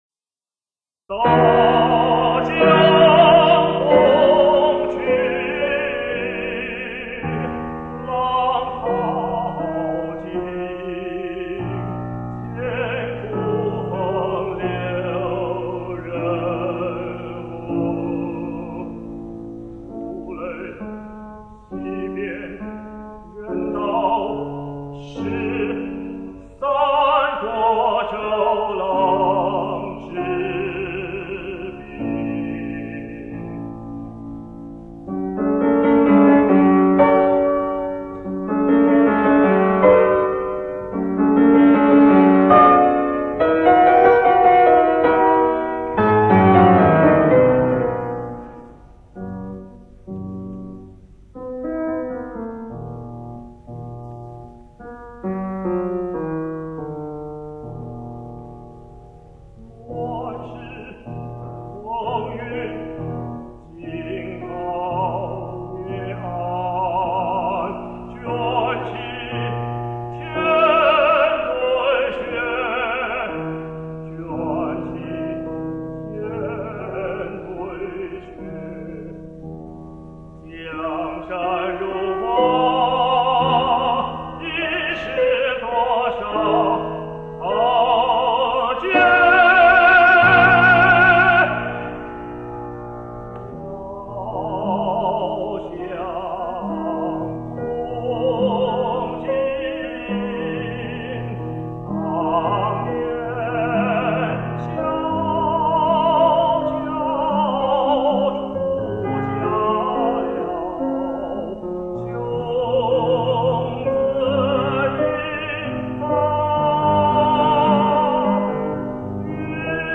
本帖自动播放的两款录音都是男高音版本。